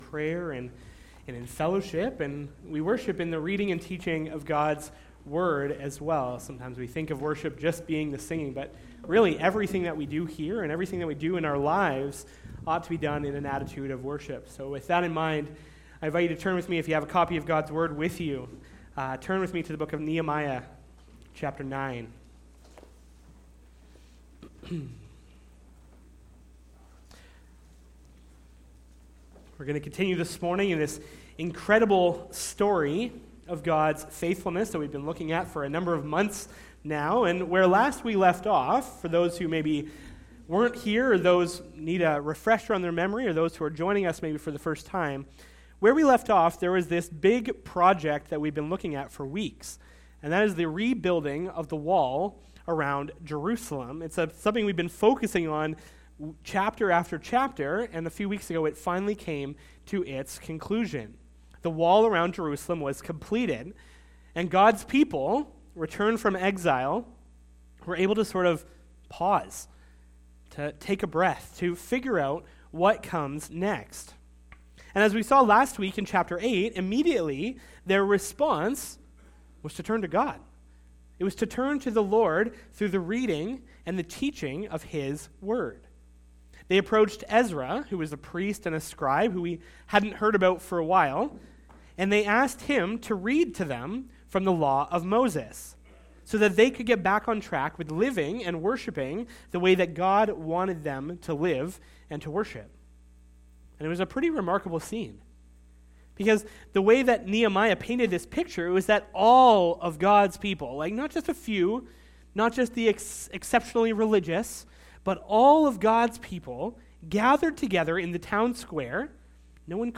Sermon Audio and Video "But You, God..."